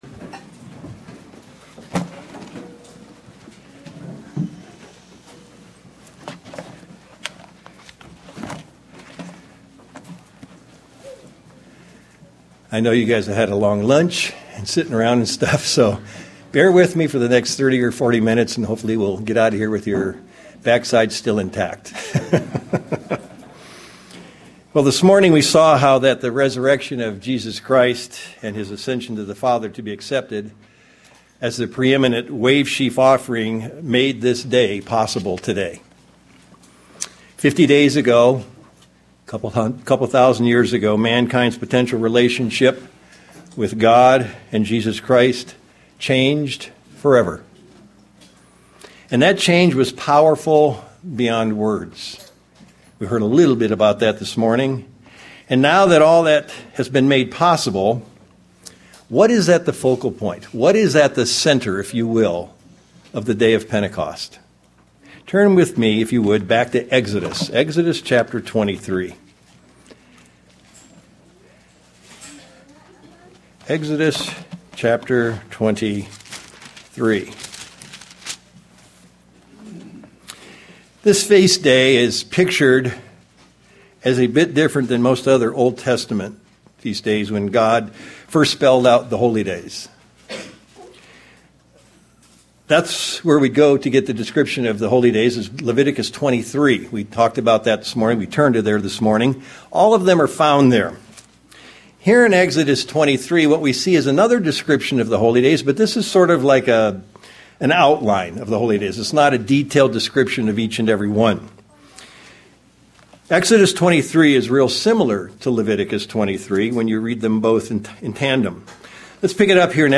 This very unique day is special in so many ways - and can be a revitalization of our spiritual lives - and bring us to spiritual unity! This message was given on the Feast of Pentecost.
View on YouTube UCG Sermon Studying the bible?